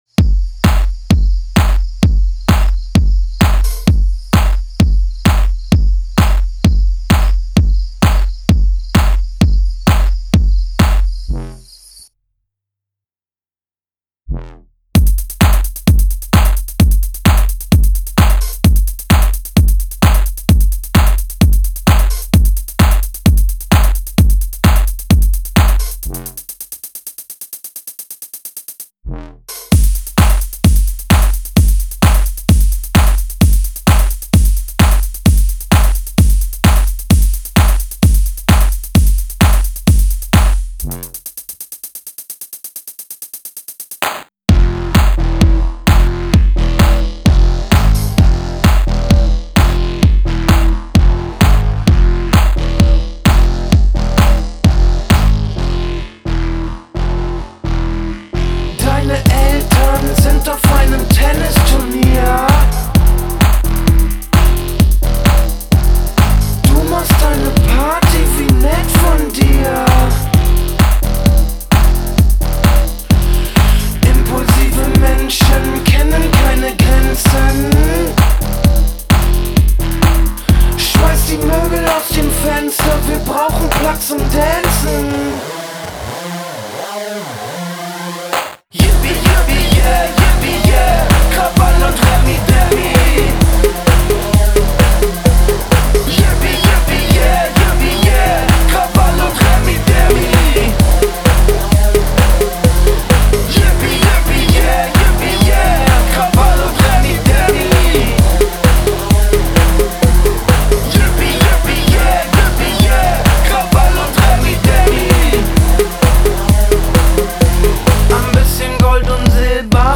Genre: Soundtrack